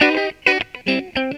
GTR 6  AM.wav